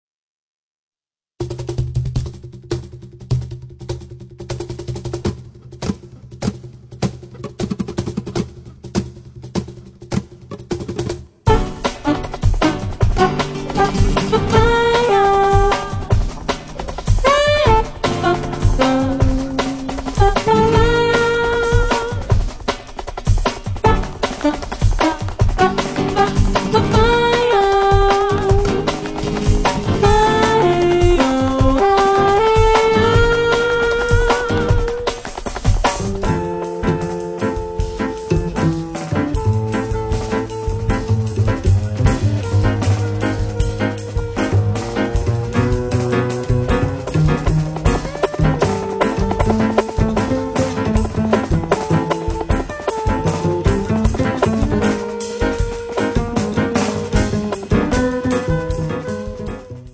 sax alto, sax soprano, programmazioni, live electronics
contrabbasso
batteria